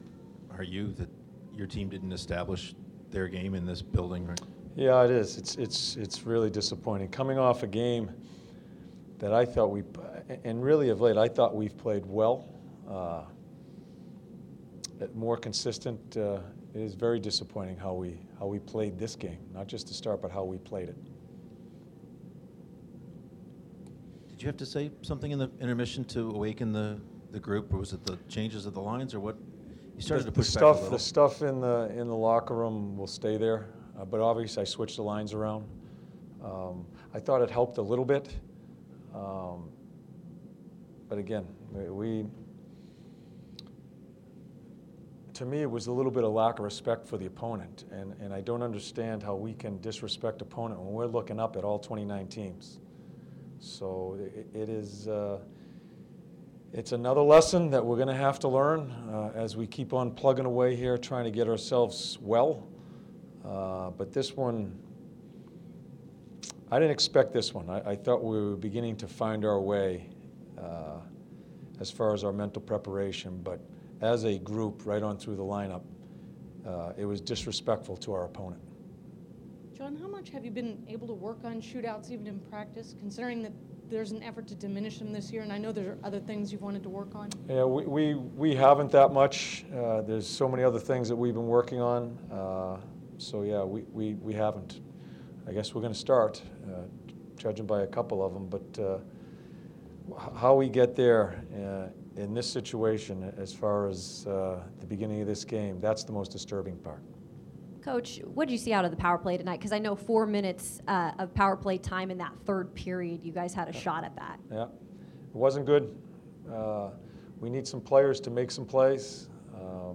John Tortorella's post game interview after the Jackets 2-1 shootout loss to the Florida Panthers inside Nationwide Arena.